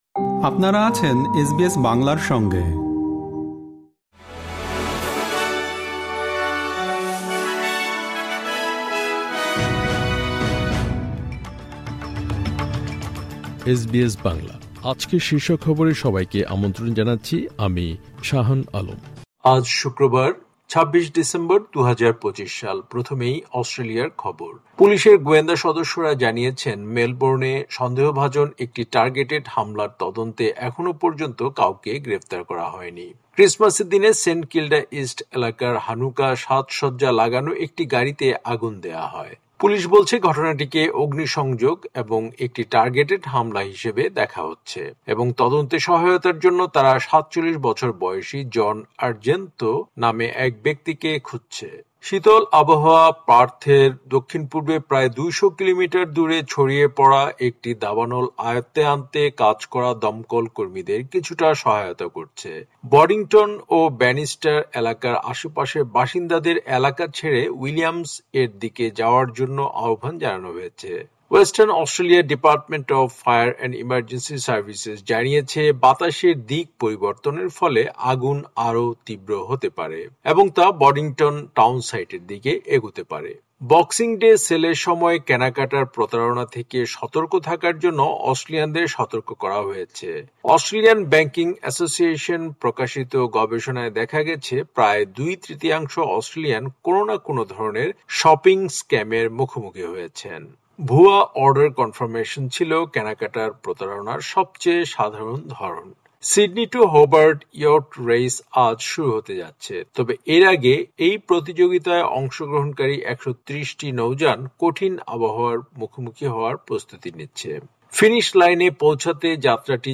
এসবিএস বাংলা শীর্ষ খবর: বক্সিং ডে সেলের সময় কেনাকাটার প্রতারণা থেকে নিরাপদ থাকতে সতর্ক করা হয়েছে